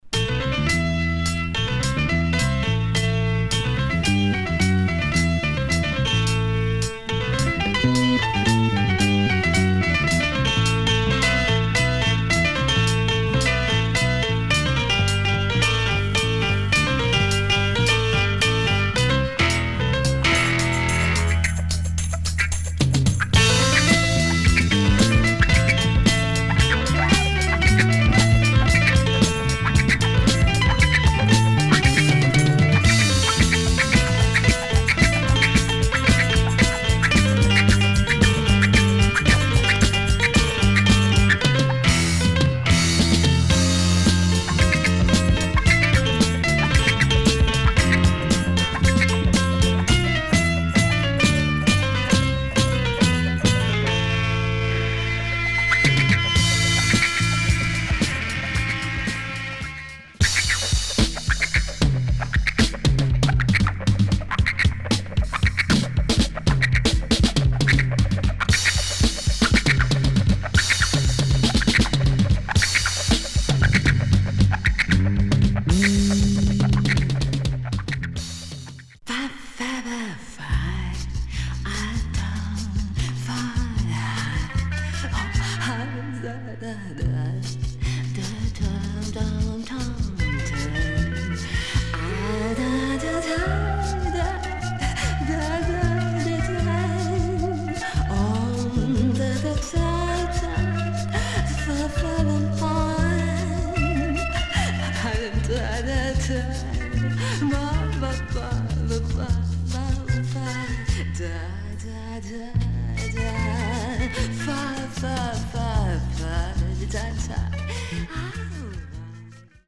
This Italian soundtrack
nice bossa track
with sensual whispering female vocals
a groovy tune with wah wah guitar and piano